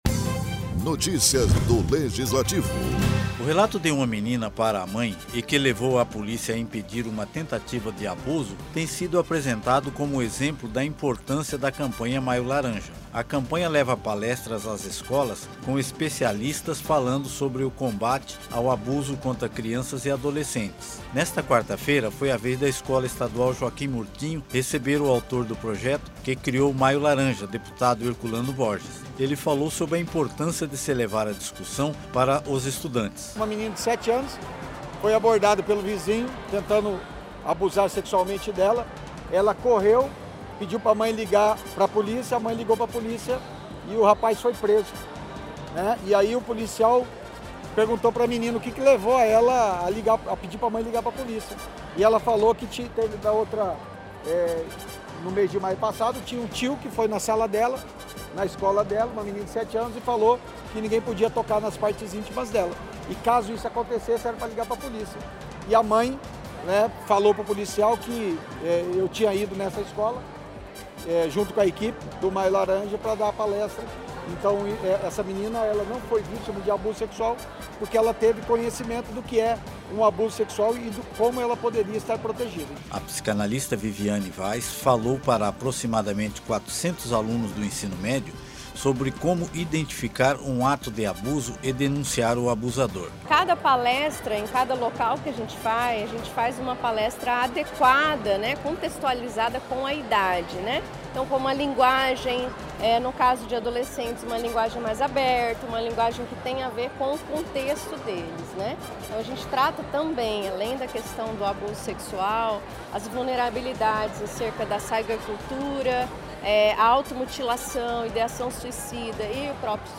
Nesta quarta-feira a campanha estava na Escola Estadual Joaquim Murtinho e o autor do projeto que criou o Maio Laranja, deputado Herculano Borges falou sobre a importância  de se levar  a discussão para as escolas.